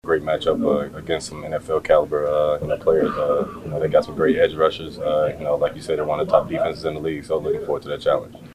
Metcalfe says the Texans will be a challenge.